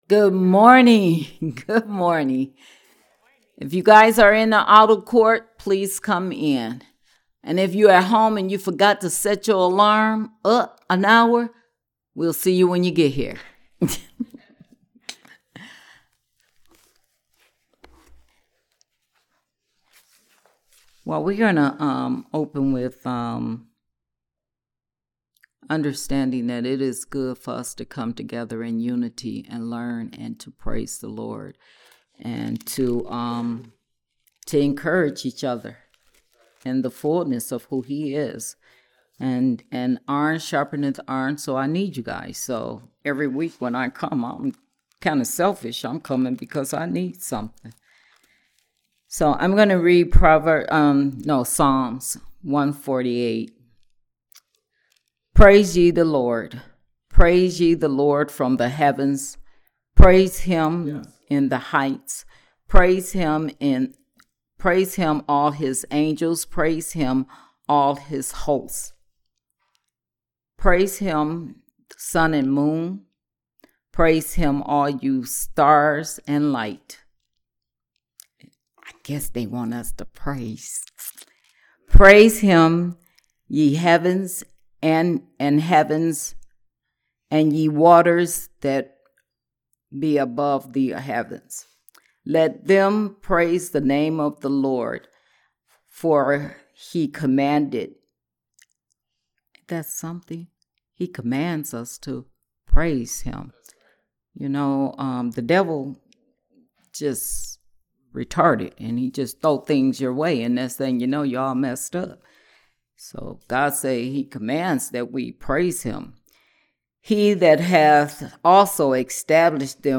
Series: Prophetic Nature of the Church Service Type: Sunday Service